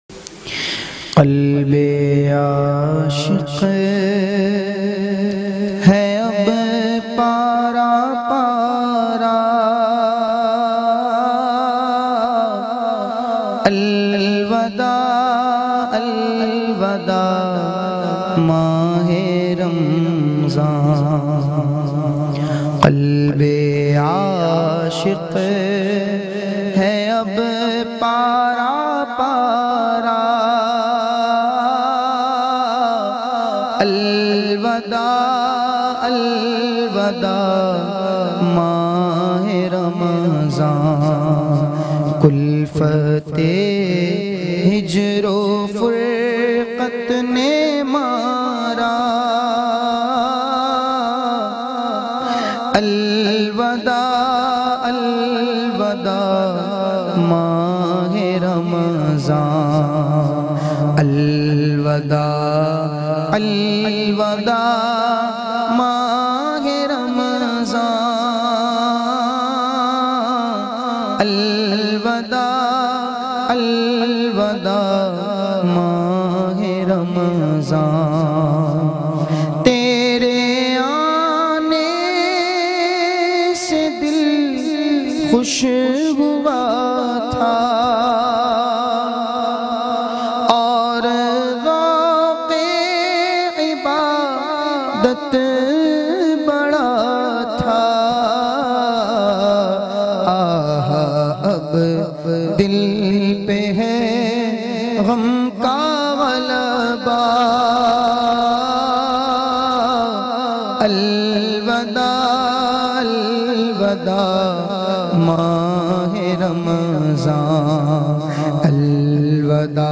Naat in a Heart-Touching Voice